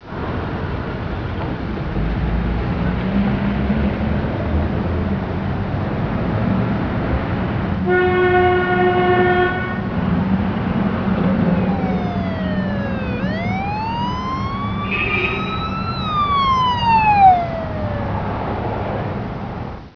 AMBIENT.WAV